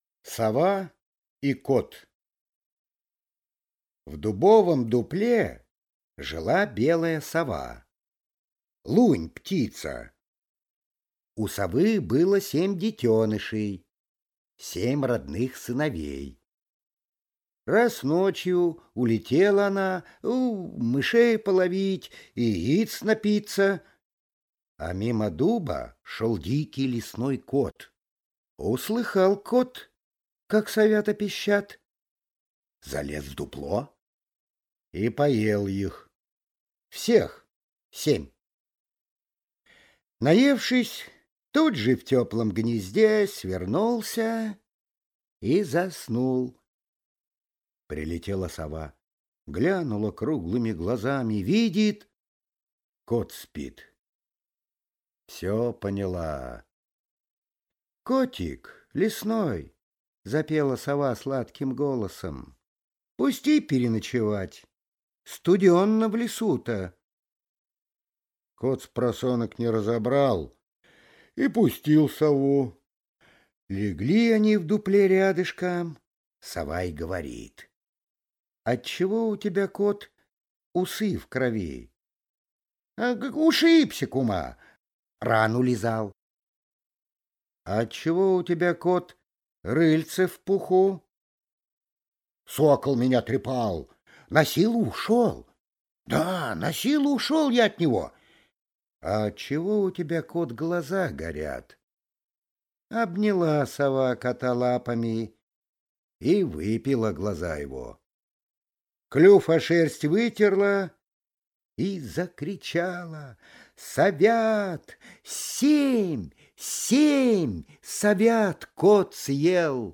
Сова и кот – Толстой А.Н. (аудиоверсия)